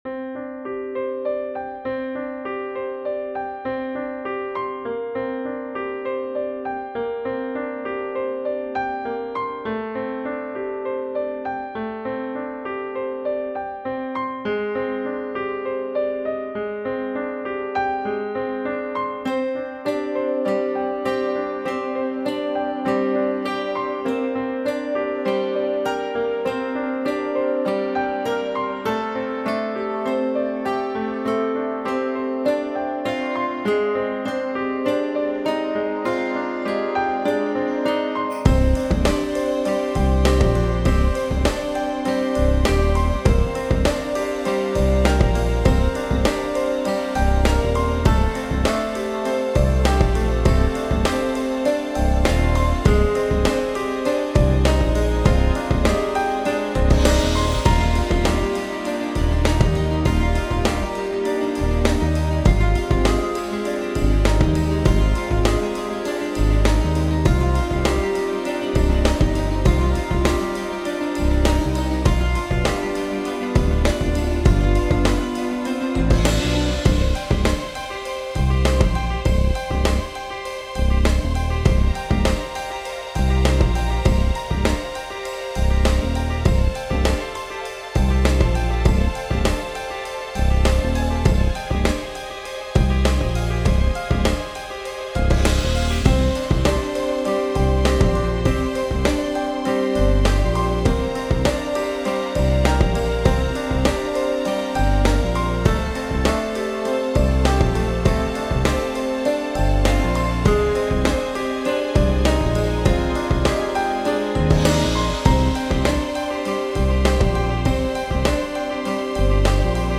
Introspection cruising on warmer roads